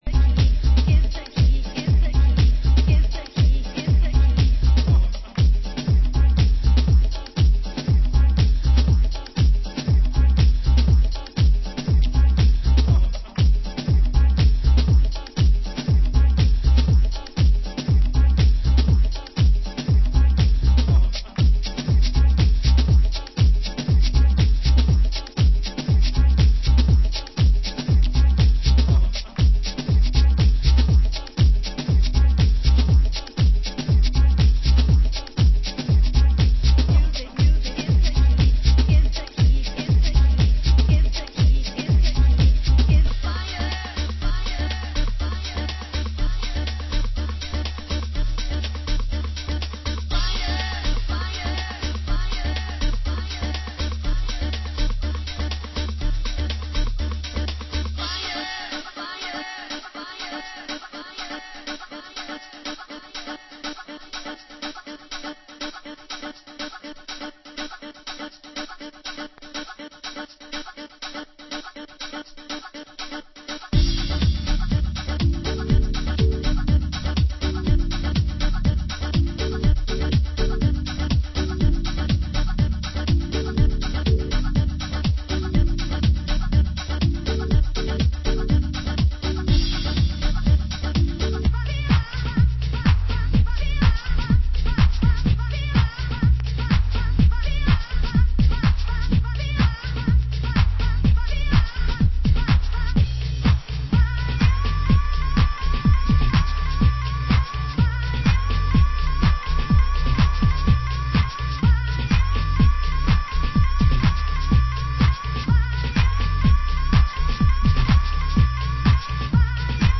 Format: Vinyl 12 Inch
Genre: Euro House